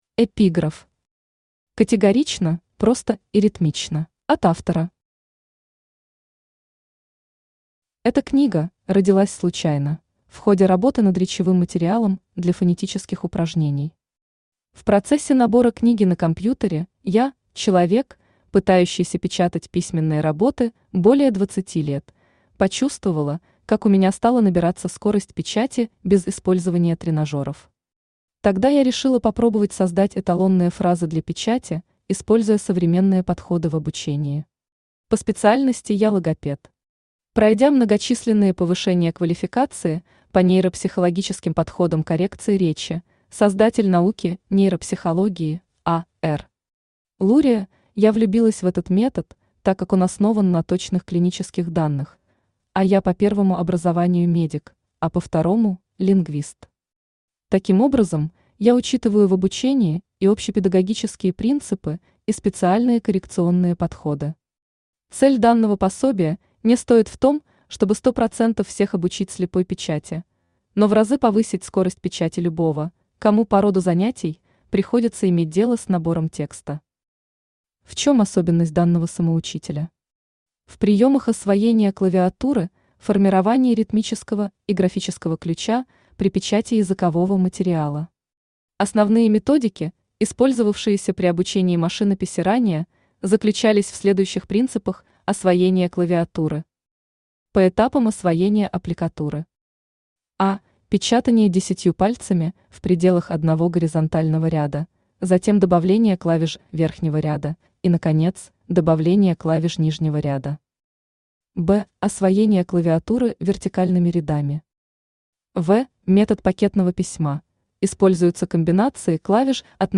Аудиокнига Бытовая машинопись. Самоучитель для самоучек (на авторских выдумках и материалах) | Библиотека аудиокниг
Самоучитель для самоучек (на авторских выдумках и материалах) Автор Мария Александровна Моткова Читает аудиокнигу Авточтец ЛитРес.